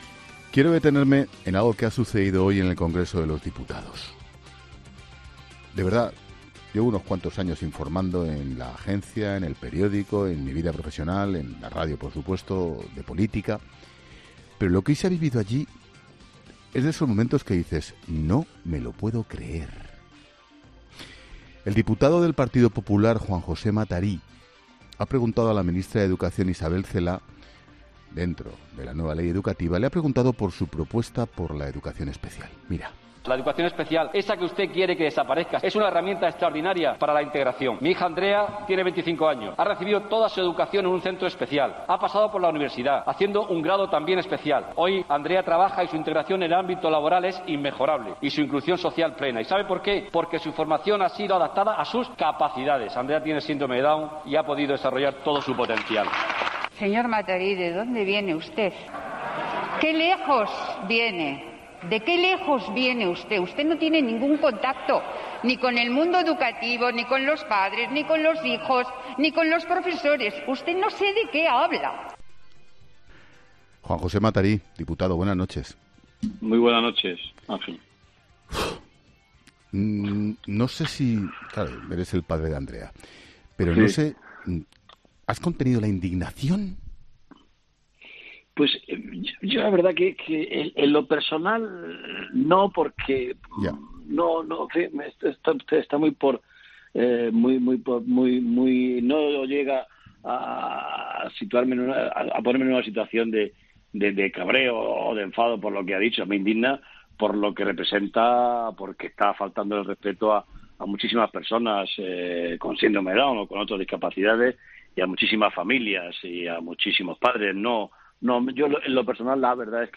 El diputado popular ha explicado a Ángel Expósito que no se siente humillado por las declaraciones de Isabel Celáa
Juan José Matarí ha pasado por los micrófonos de 'La Linterna' donde ha explicado que aunque él personalmente no se siente ofendido, sí le parece que las declaraciones de la ministra suponen "una falta de respeto a muchas familias con hijos discapacitados".